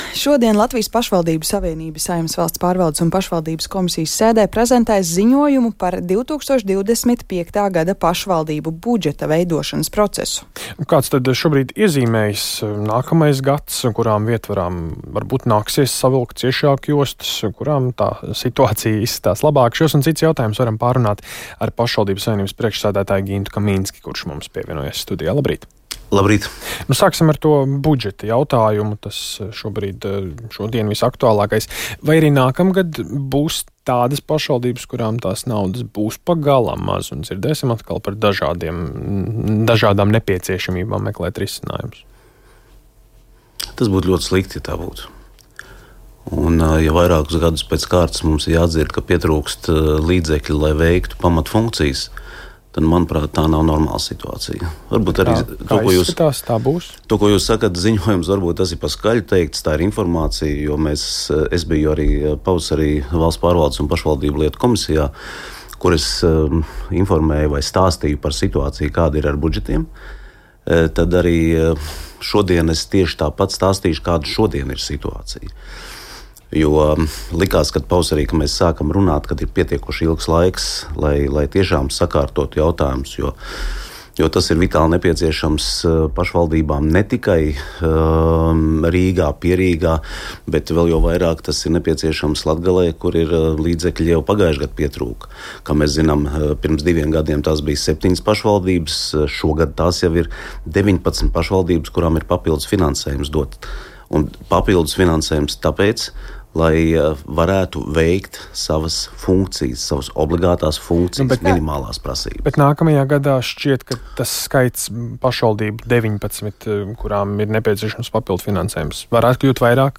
Tādu ainu intervijā Latvijas Radio raidījumā Labrīt atklāja Latvijas Pašvaldību savienības priekšsēdis Gints Kaminskis.